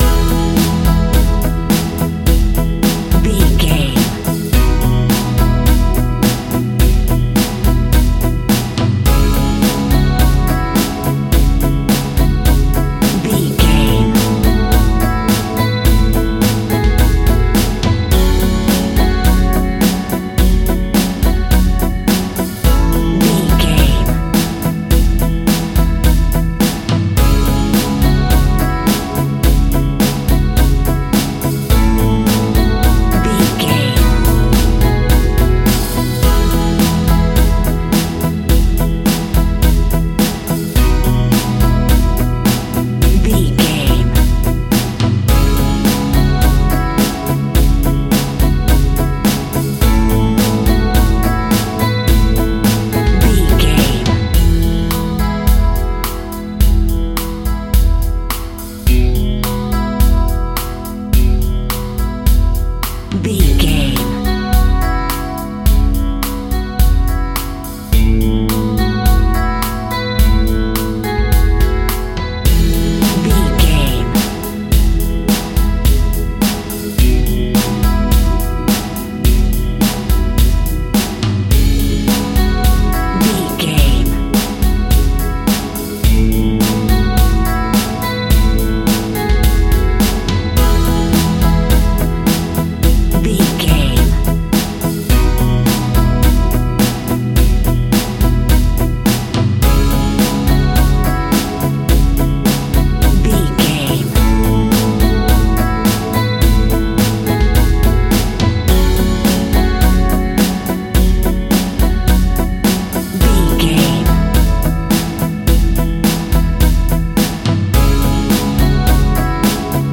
Pop Rock in the Eighties.
Ionian/Major
fun
energetic
uplifting
cheesy
synths
drums
bass
guitar
piano
keyboards